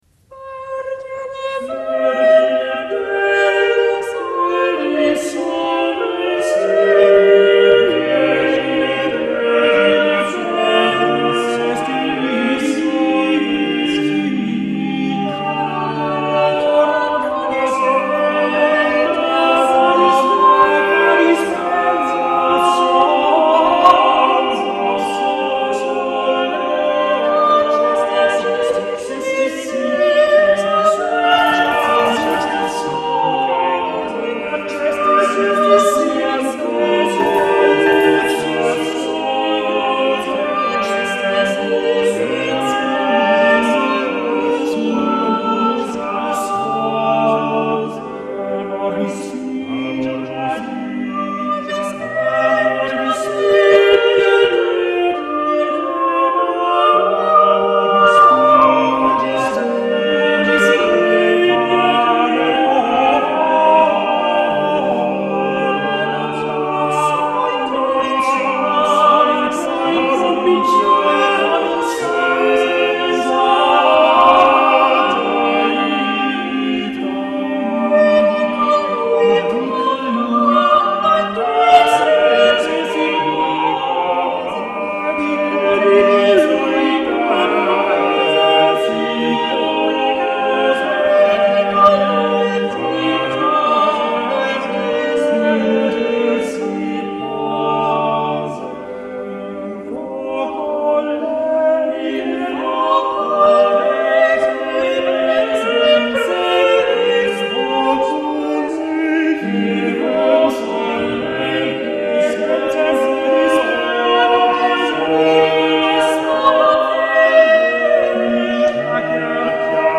Madrigale spirituale
Madrigale spirituale 1534 - 1654 (Late Renaissance, Early Baroque) Italy Group: Madrigal A madrigal, or madrigal-like piece of music, with a sacred rather than a secular text.
Musica Renacentista Madrigali Spirituali a 5 voci